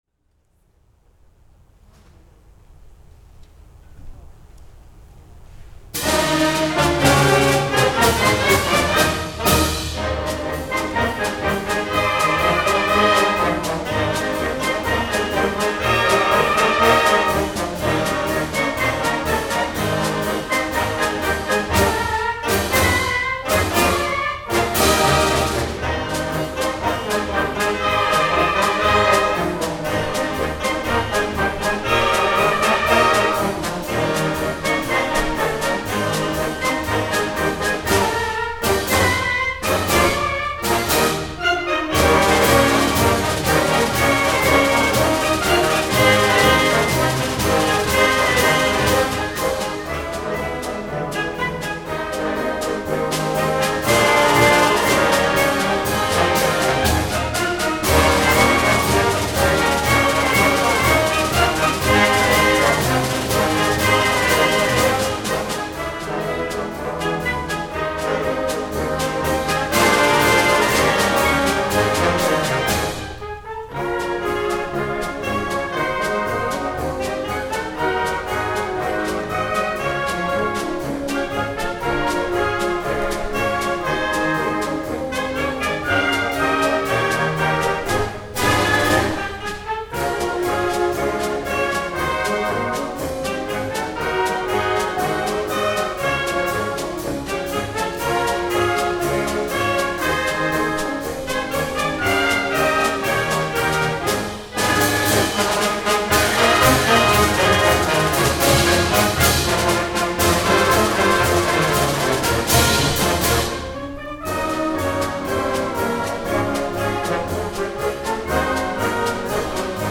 The LBB's winter concert was on Sunday March 6th 2022 at the Lexington High School in Lexington.